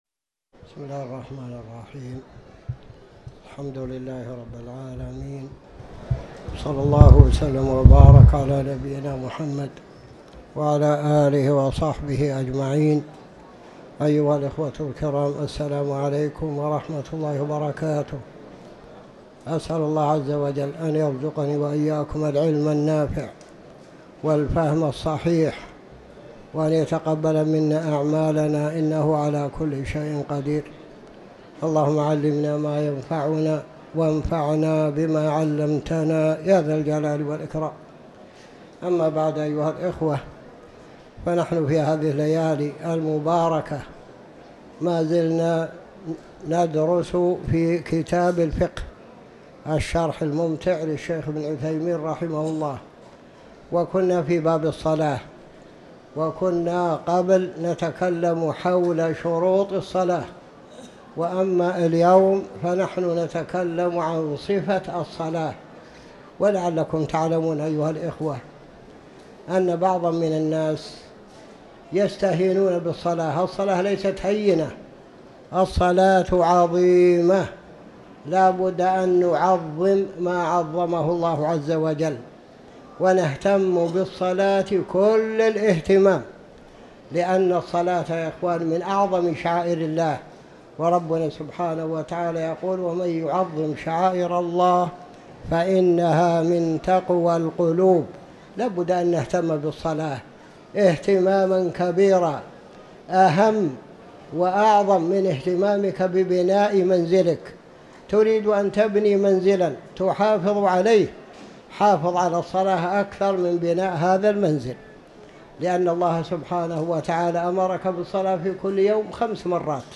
تاريخ النشر ٢٣ شوال ١٤٤٠ هـ المكان: المسجد الحرام الشيخ